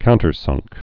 (kountər-sŭngk)